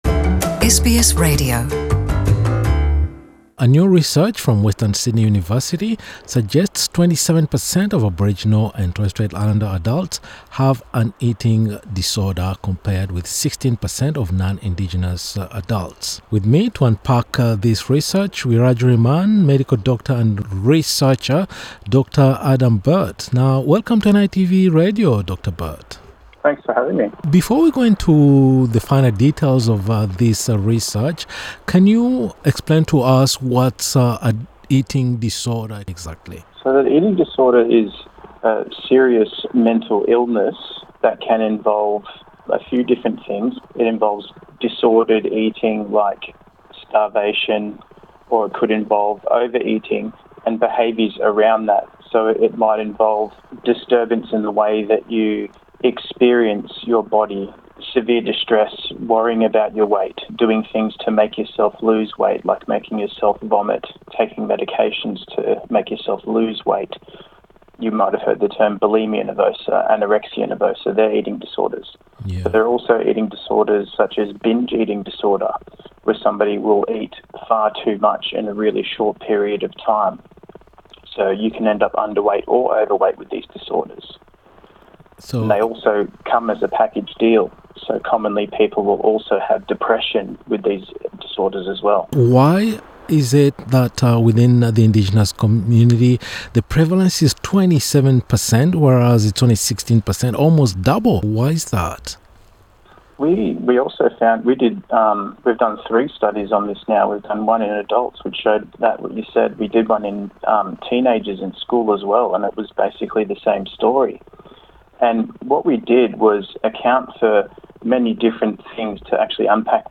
SBS NITV Radio